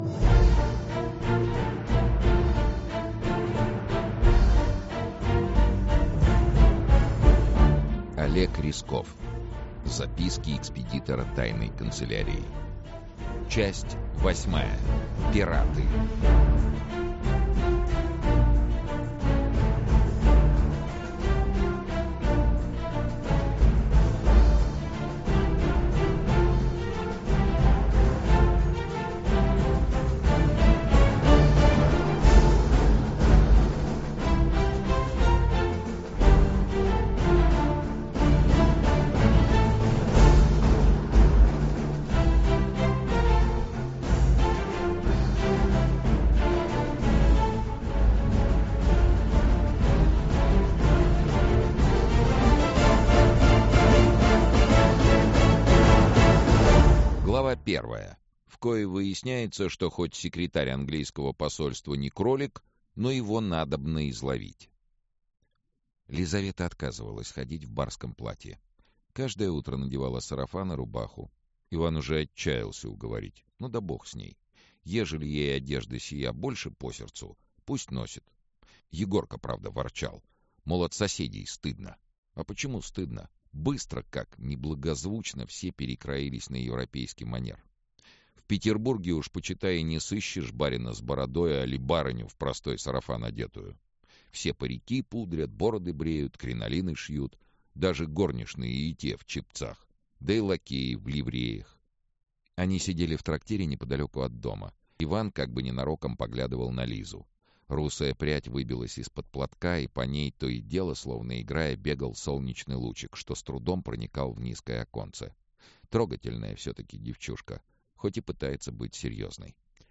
Пираты Автор Олег Рясков Читает аудиокнигу Сергей Чонишвили.